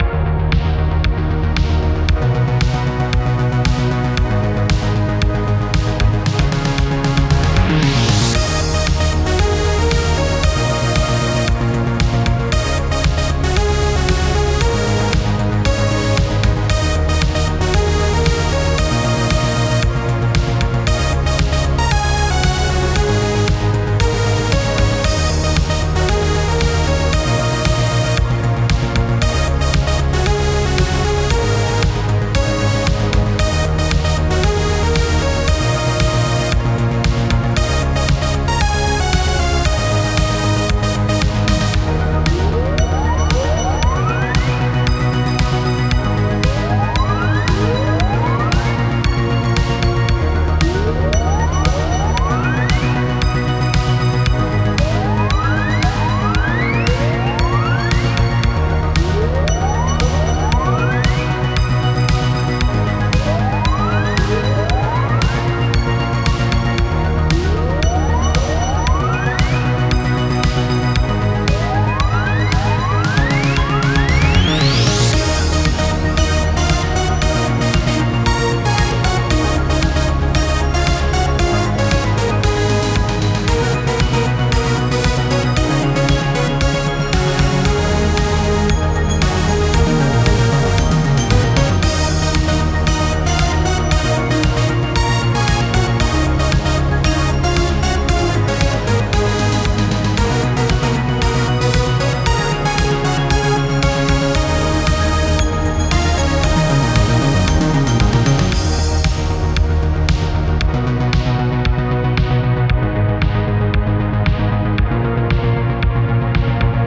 Synthwave Music for City theme.